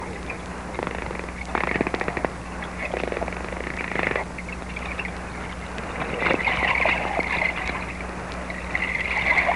دانلود صدای دلفین 3 از ساعد نیوز با لینک مستقیم و کیفیت بالا
جلوه های صوتی